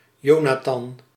Dutch: [ˈjoːnaːtɑn]
Nl-Jonathan.ogg.mp3